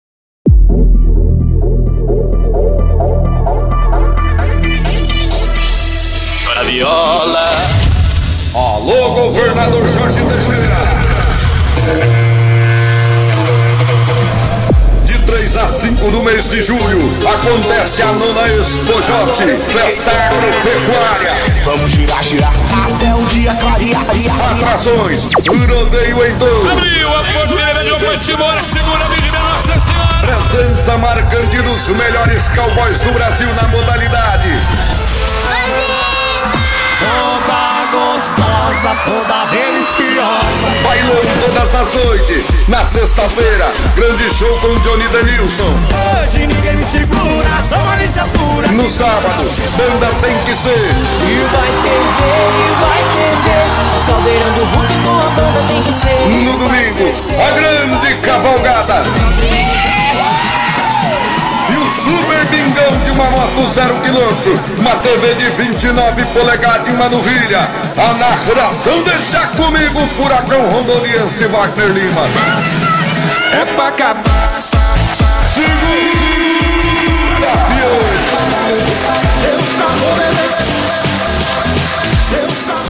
SPOT-EXPOJOT-2015-2.mp3